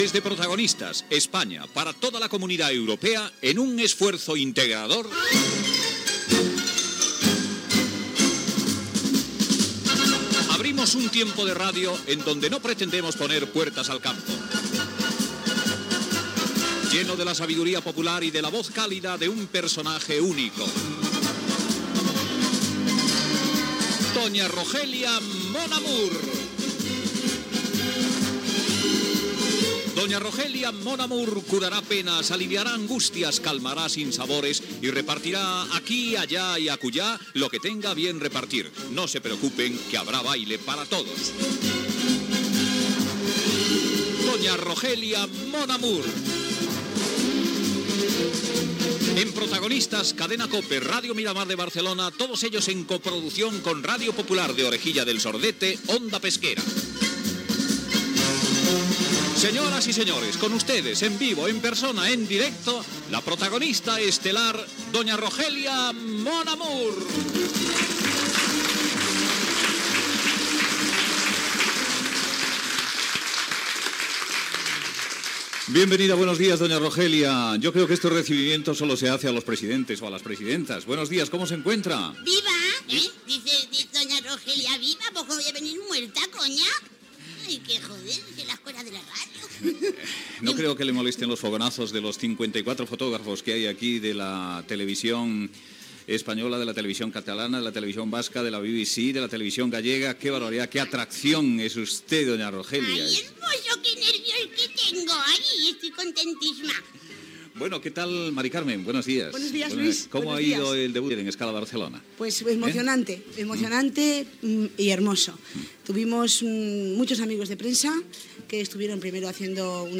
Secció "Doña Rogelia mon amour". Presentació i di¡aleg inicial
Info-entreteniment